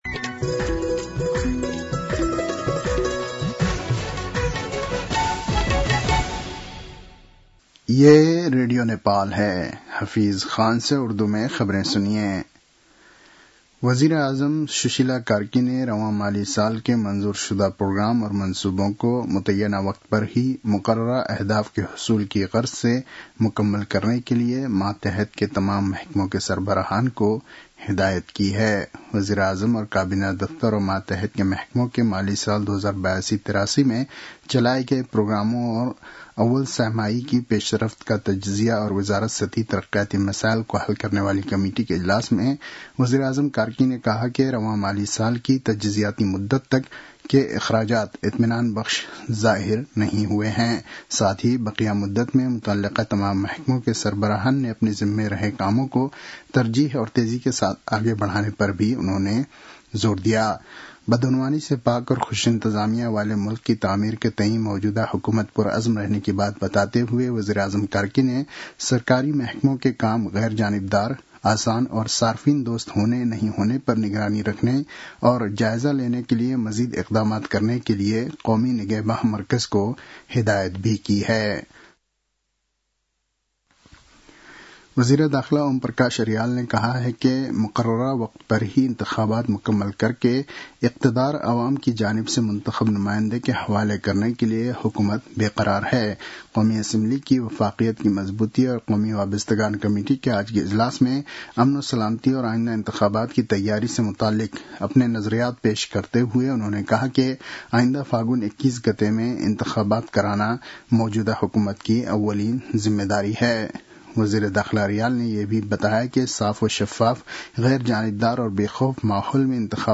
उर्दु भाषामा समाचार : २७ कार्तिक , २०८२